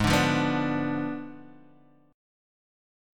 Bdim/Ab chord
B-Diminished-Ab-4,5,3,4,3,x.m4a